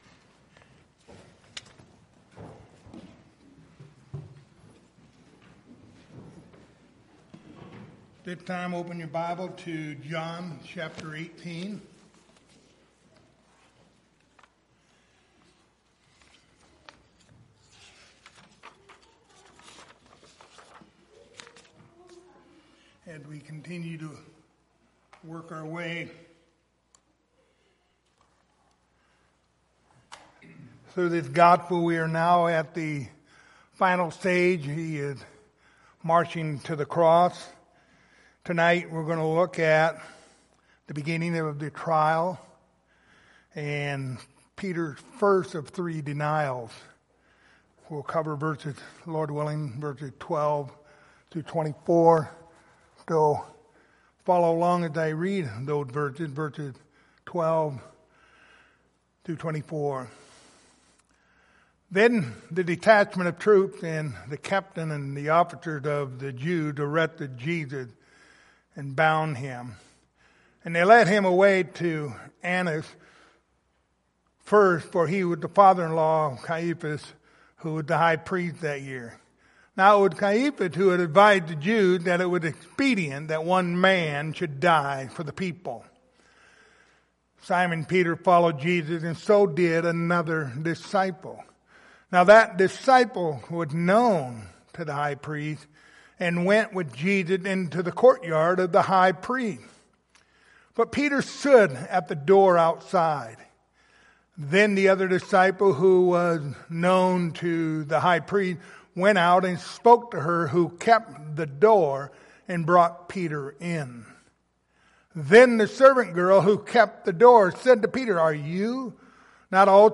Passage: John 18:12-24 Service Type: Wednesday Evening